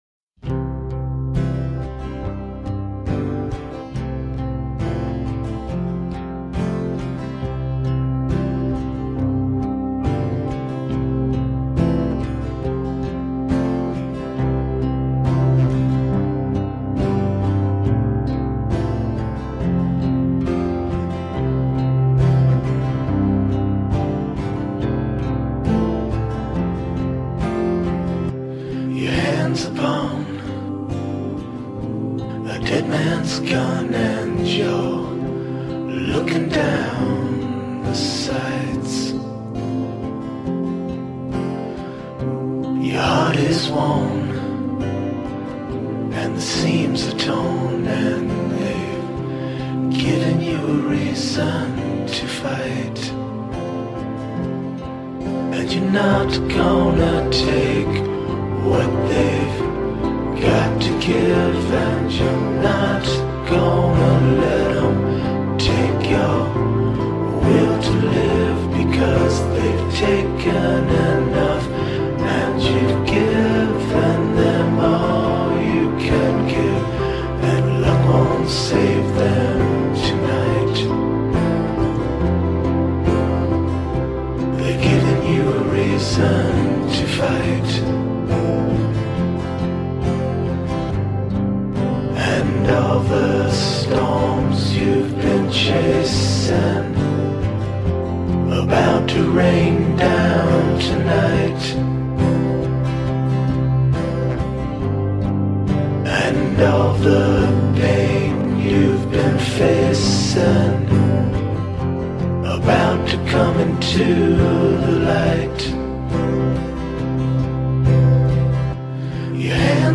And gosh, what an emotion release you get.